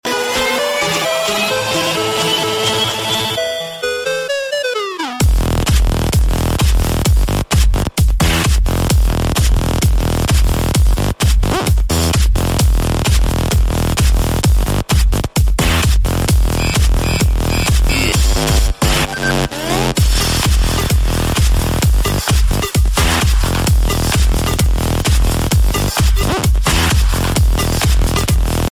Catégorie Jeux